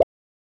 Pop (3).wav